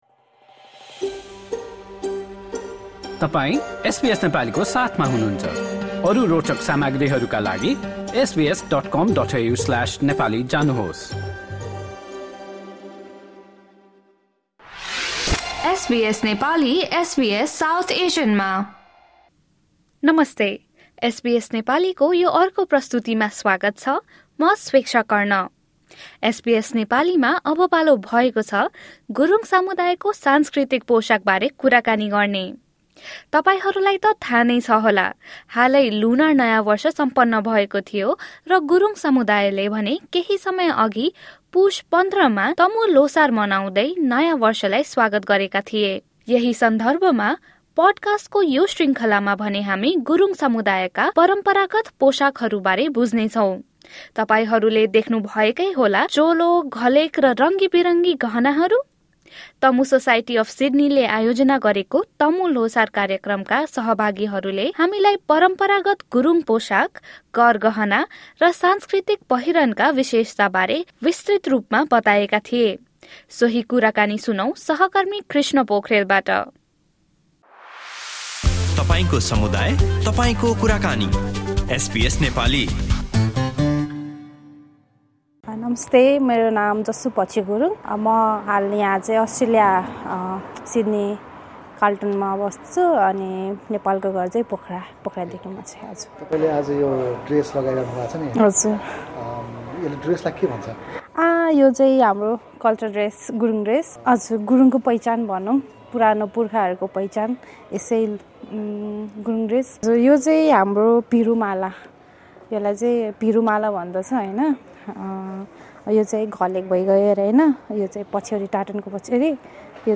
हालै लुनार नयाँ वर्ष सम्पन्न भएको थियो र त्यसैसँगै गुरुङ समुदायले भने पुष १५ मा तमु ल्होसार मनाउँदै नयाँ वर्षलाई स्वागत गरेका थिए। यसै क्रममा, तमु सोसाइटी अफ सिड्नीले आयोजना गरेको तमु ल्होसार कार्यक्रमका सहभागीहरूले आफ्ना परम्परागत गुरुङ पोसाक, गरगहना, र सांस्कृतिक पहिरनका विशेषताबारे एसबीएस नेपालीसँग गरेको कुराकानी सुन्नुहोस्।